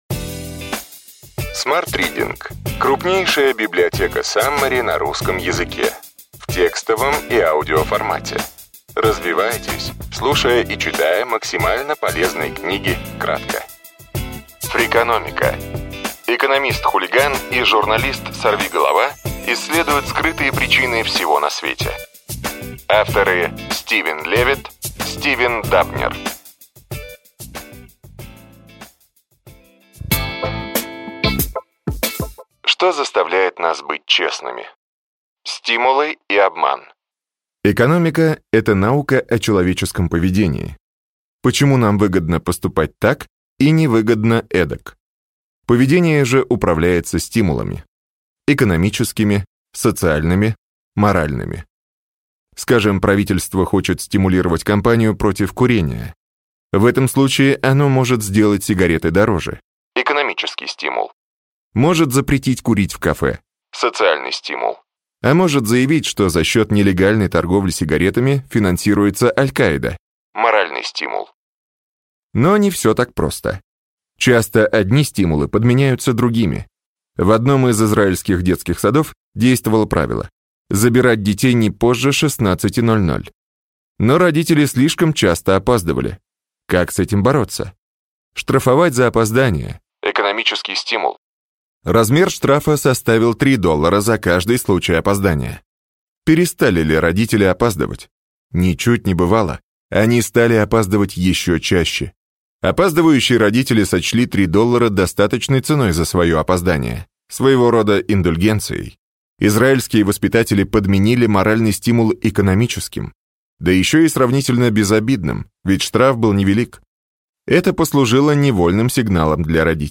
Аудиокнига Ключевые идеи книги: Фрикономика. Экономист-хулиган и журналист-сорвиголова исследуют скрытые причины всего на свете.